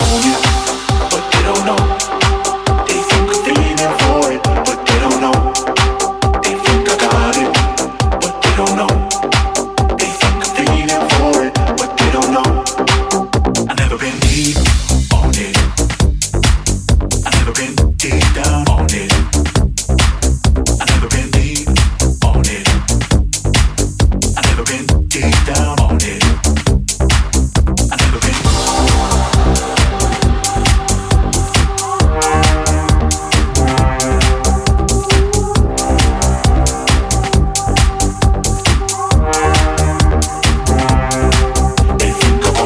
Genere: deep, dance, edm, club, remix